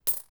Coins2.wav